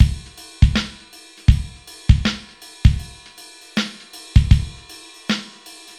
Index of /90_sSampleCDs/AKAI S6000 CD-ROM - Volume 4/Others-Loop/BPM_80_Others1